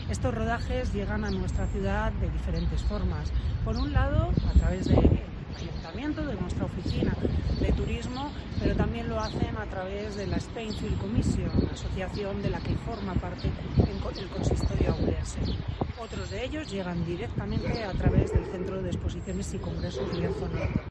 Lo cuenta así la teniente de alcalde Turismo y Cultura, Sonsoles Prieto (ESCUCHAR).